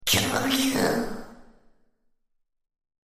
Enemy_Voice_Abyssal_Landing_Imp_Under_Attack.mp3